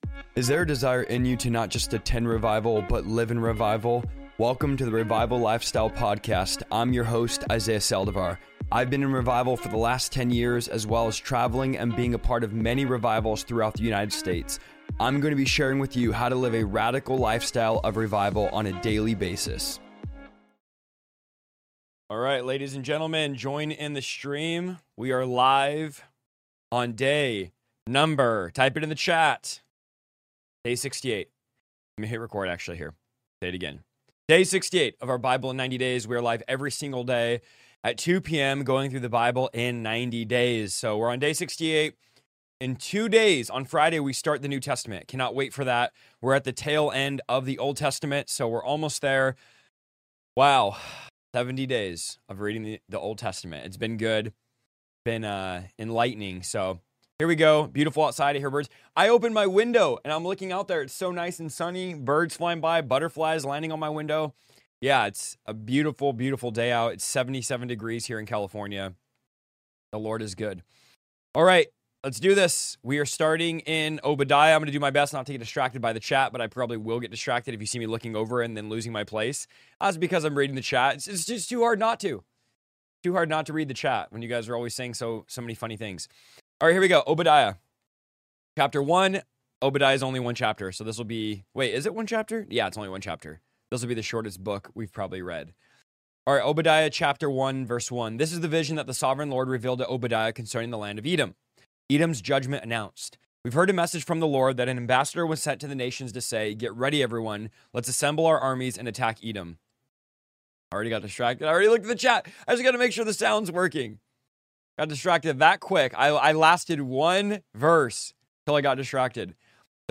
Every day, we'll dive into Scripture together in a relaxed, interactive live session where you can ask questions, share thoughts, and explore the Bible in a way that feels personal and genuine. Here’s what you can look forward to: Daily Bible Reading: I'll be reading through the Bible live, sharing my insights and reflections as we move chapter by chapter.